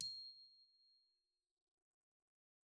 glock_medium_C7.wav